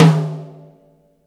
079 - Tom-4.wav